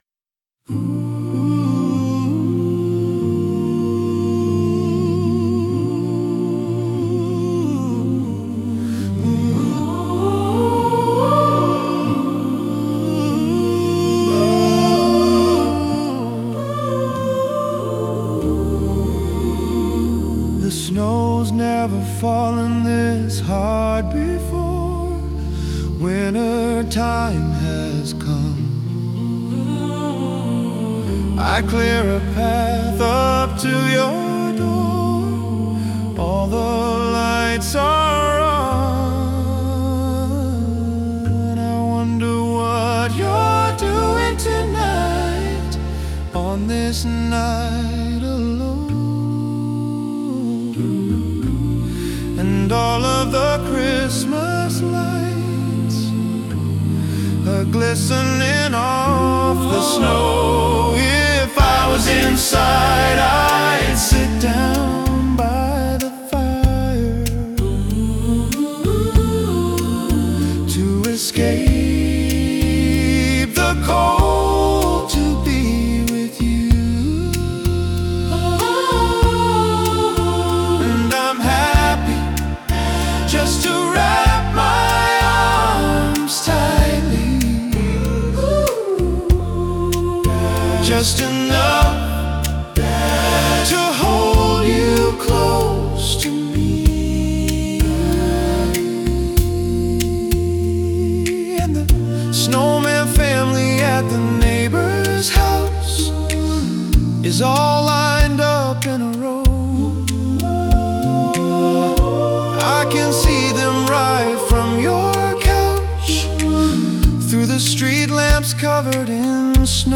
Warm, intimate, acoustic-driven holiday vibe
• ≈ 70–76 BPM